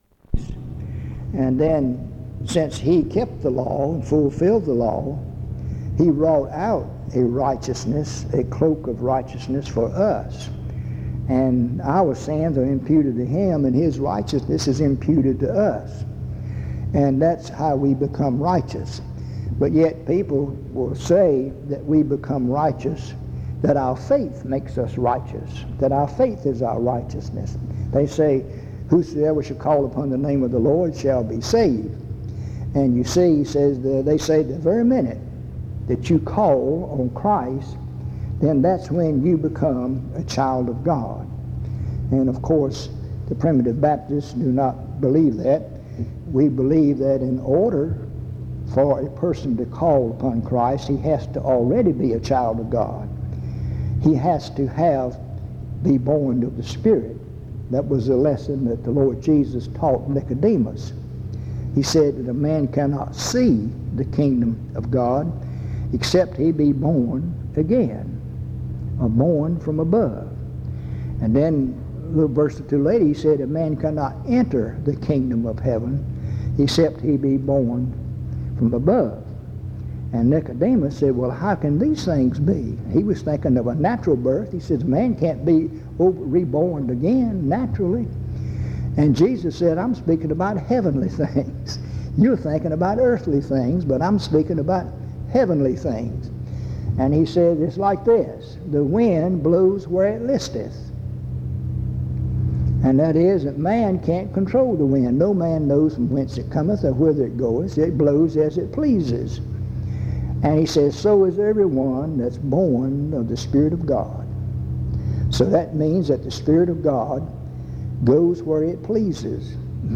at Reidsville Lindsey St. Primitive Baptist Church